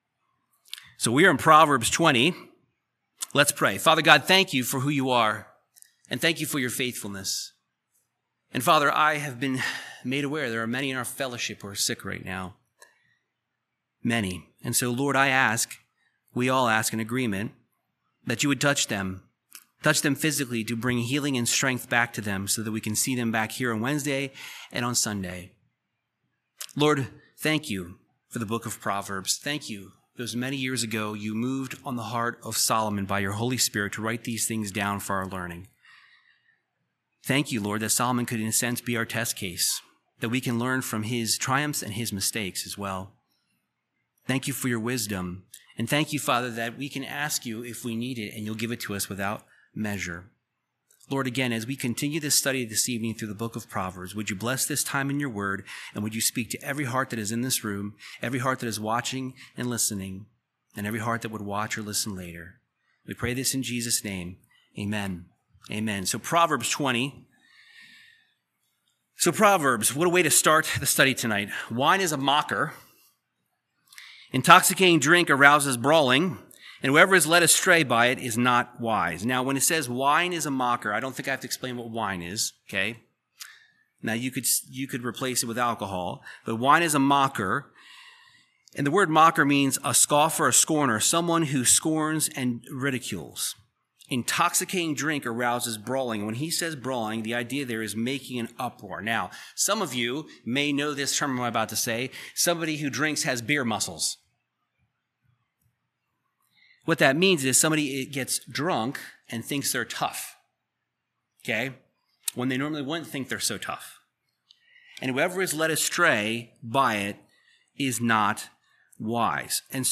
Verse by verse Bible teaching through the book of Proverbs chapter 20 discussing the wise sayings of King Solomon recorded there.